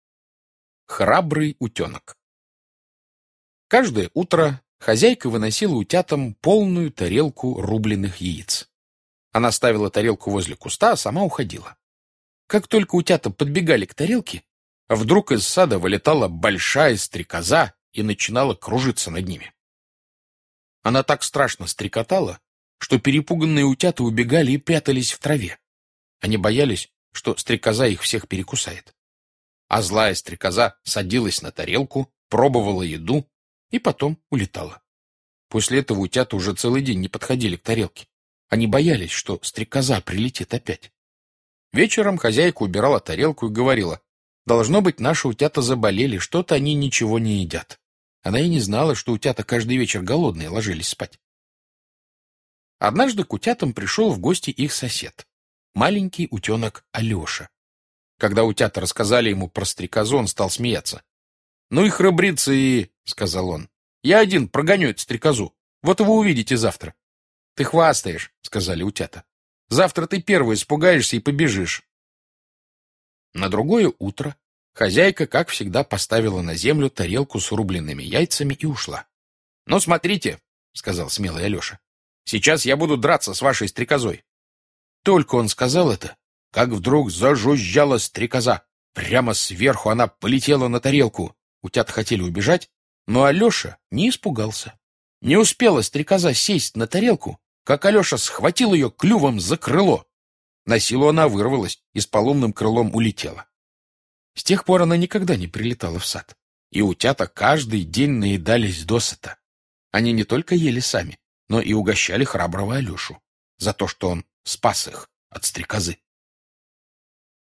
Аудиорассказ «Храбрый утенок»